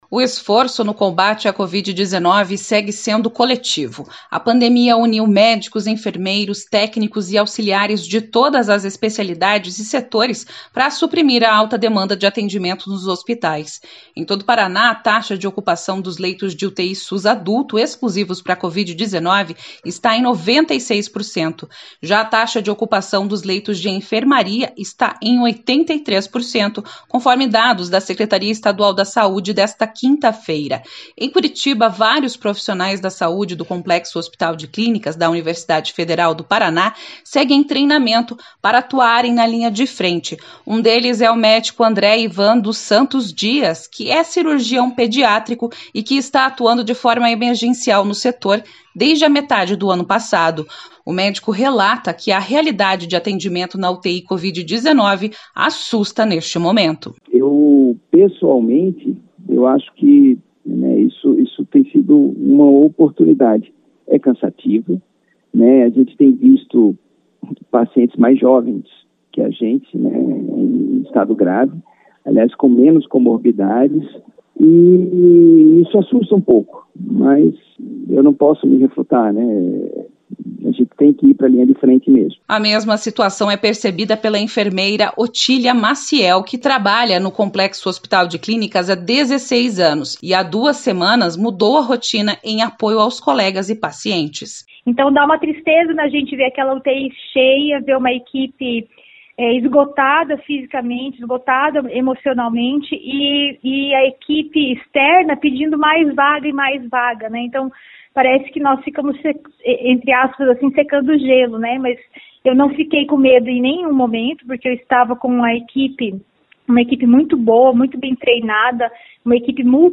Médico relata experiência de atendimento em UTI covid-19, e afirma que estamos à beira da calamidade.// Confira na reportagem.